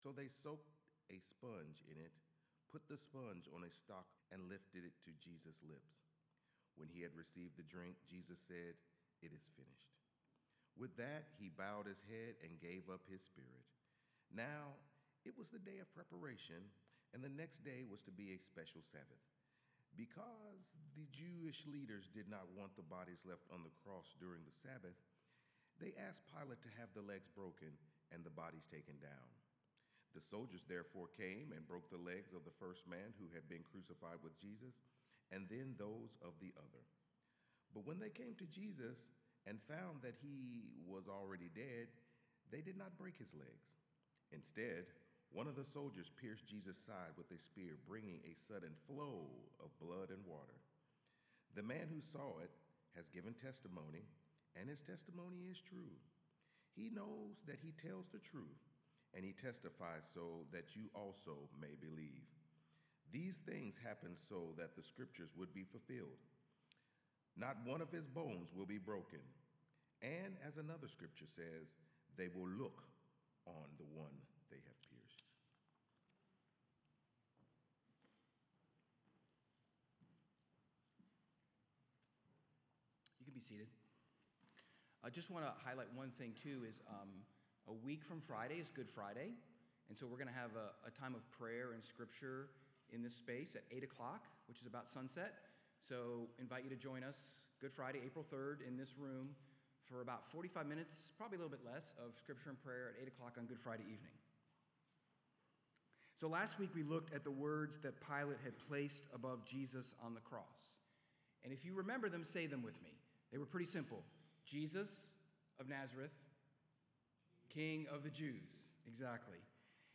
Sermons | Westport Road Church of Christ